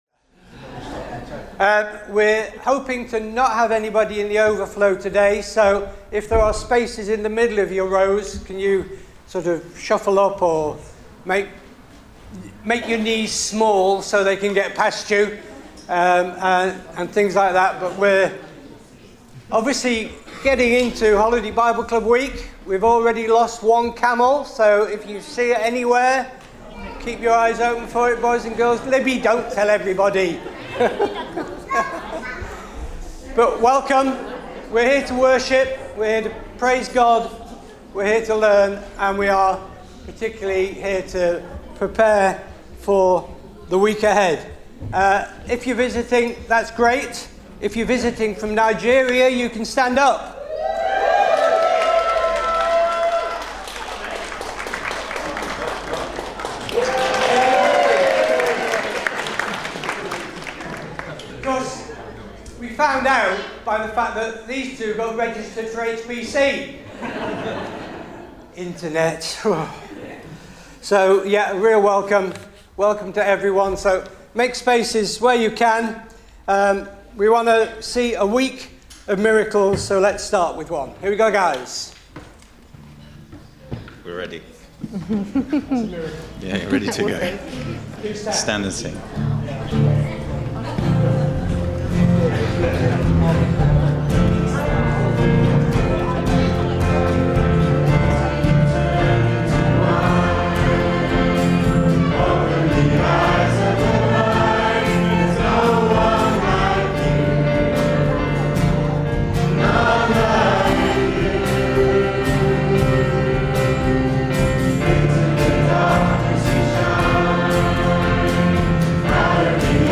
27 July 2025 – Morning Service